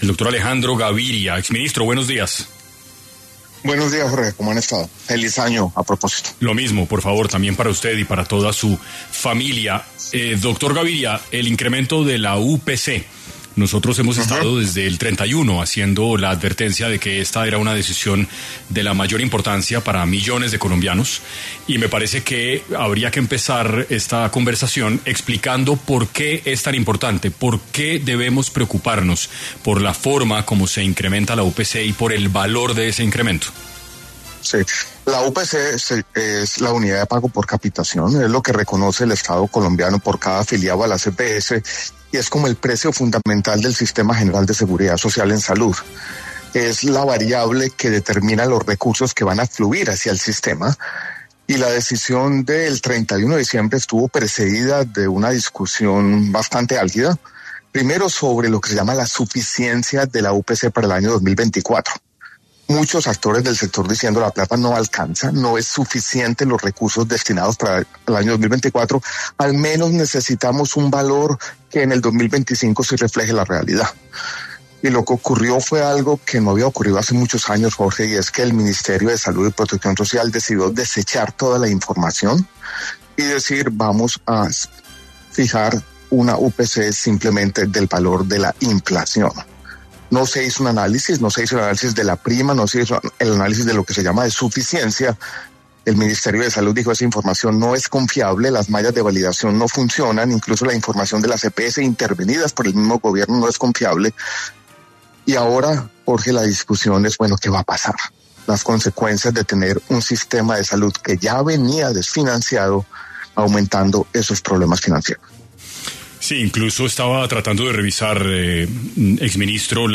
En 6AM de Caracol Radio estuvo Alejandro Gaviria, exministro de Salud, quien habló sobre por qué asegura que el gobierno Petro está “politizando la salud” y qué podría generar el aumento de la UPC para el sistema de salud colombiano.